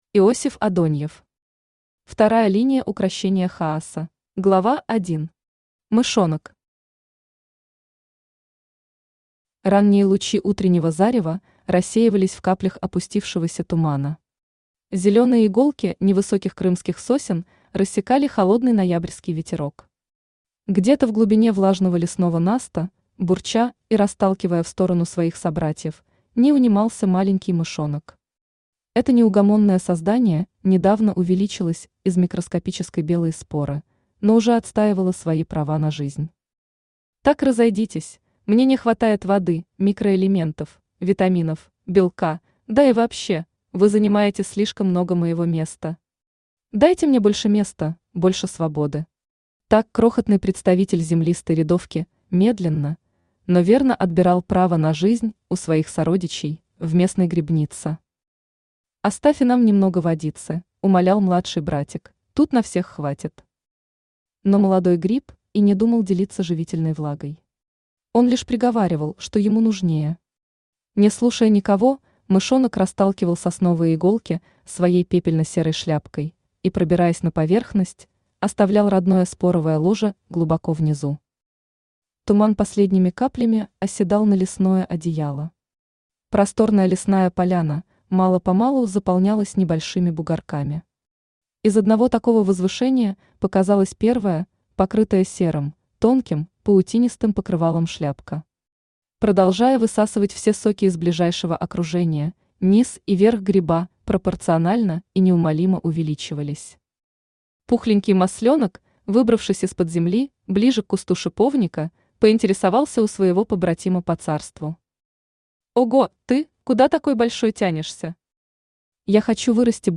Аудиокнига Вторая линия укрощения хаоса | Библиотека аудиокниг
Aудиокнига Вторая линия укрощения хаоса Автор Иосиф Александрович Адоньев Читает аудиокнигу Авточтец ЛитРес.